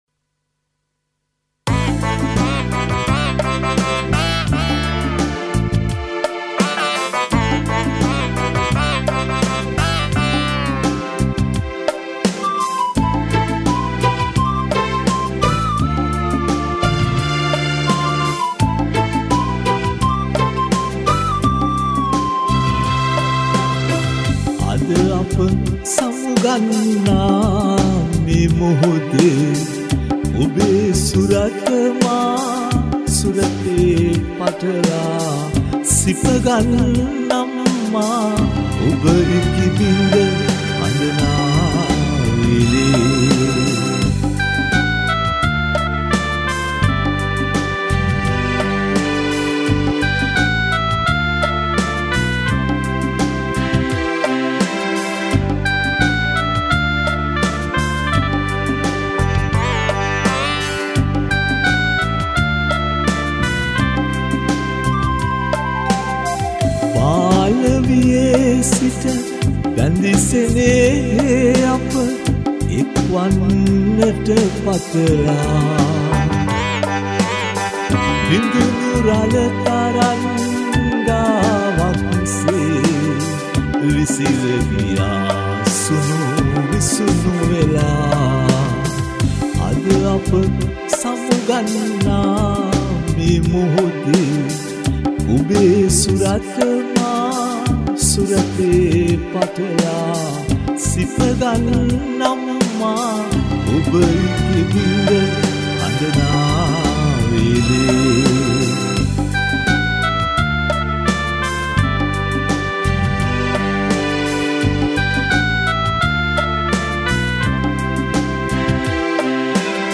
All these songs were recorded (or remastered) in Australia.